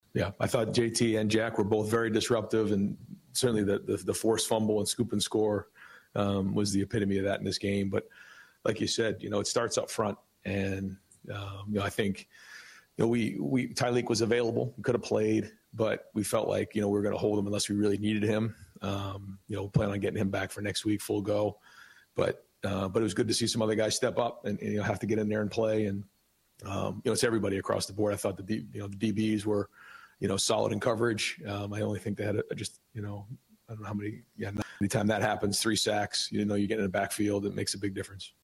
EXCERPTS FROM RYAN DAY’S POSTGAME PRESS CONFERENCE